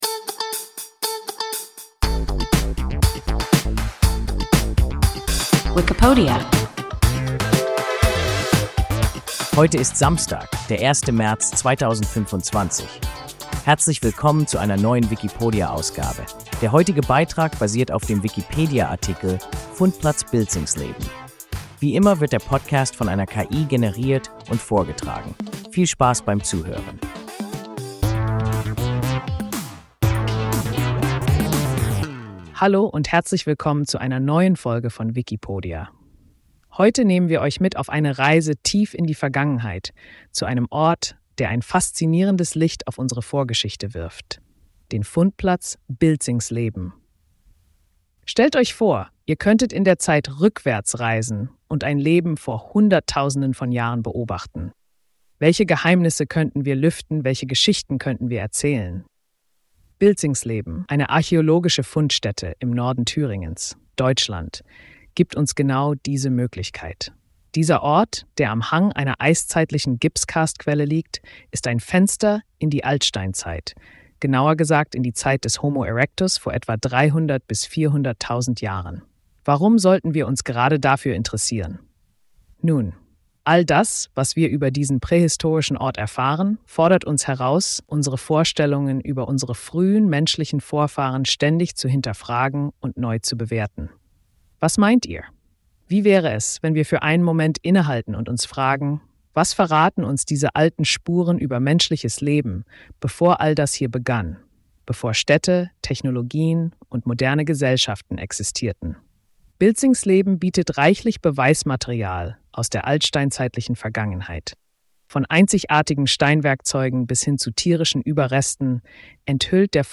Fundplatz Bilzingsleben – WIKIPODIA – ein KI Podcast